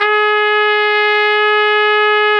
Index of /90_sSampleCDs/Roland L-CD702/VOL-2/BRS_Tpt 5-7 Solo/BRS_Tp 5 RCA Jaz